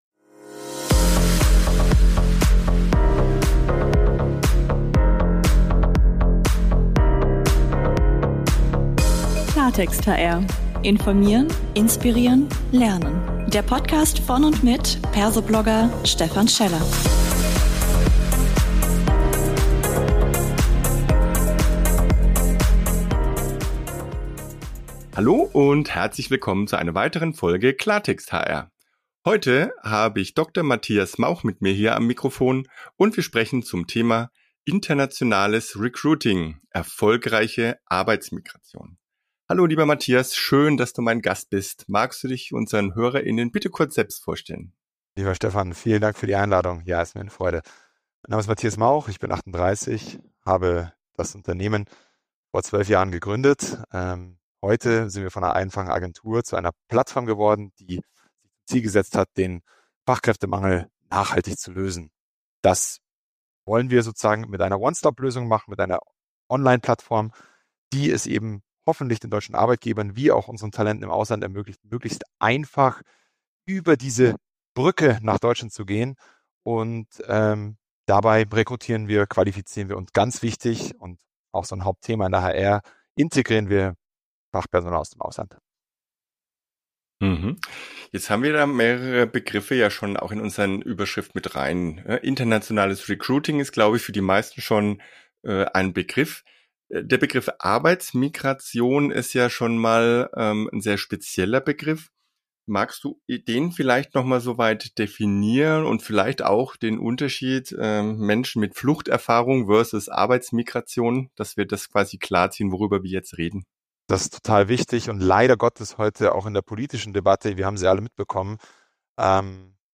Ein spannender Talk als 15-Minuten-Impuls.